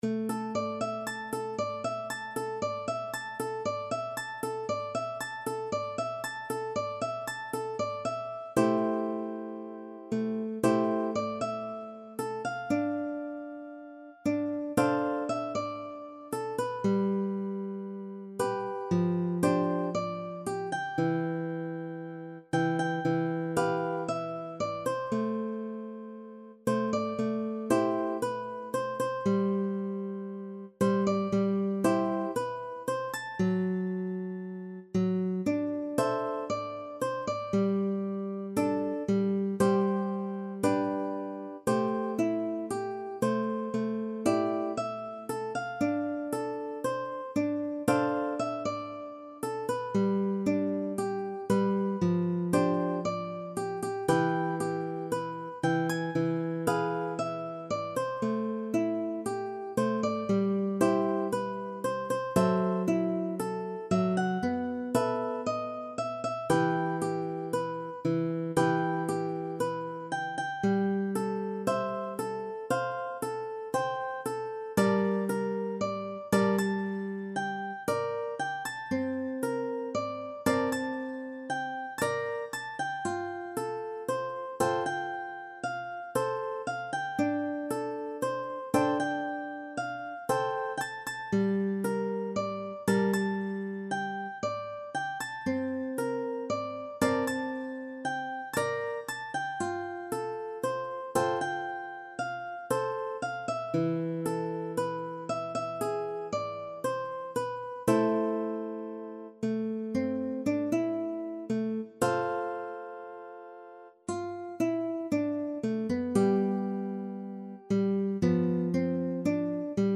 J-POP / ポップス / 女性ソロ
楽譜の音源（デモ演奏）は下記URLよりご確認いただけます。
（この音源はコンピューターによる演奏ですが、実際に人が演奏することで、さらに表現豊かで魅力的なサウンドになります！）